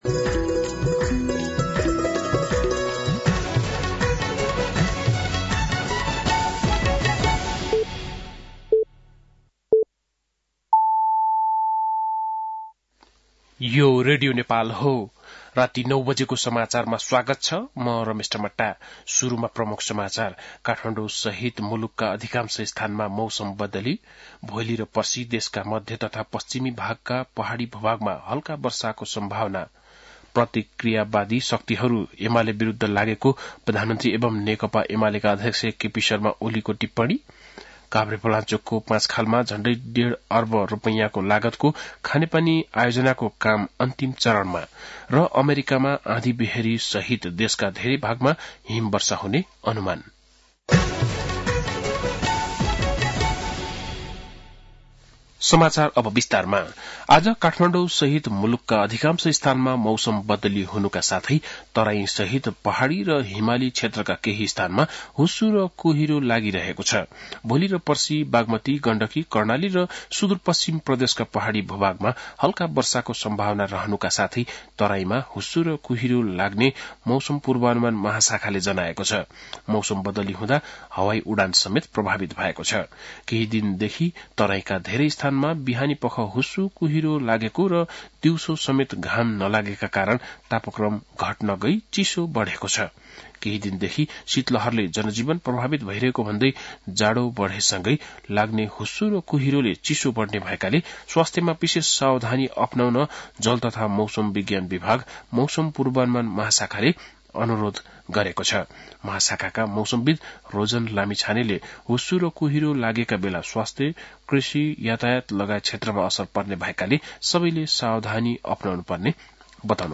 बेलुकी ९ बजेको नेपाली समाचार : २२ पुष , २०८१
9-PM-Nepali-NEWS-9-21.mp3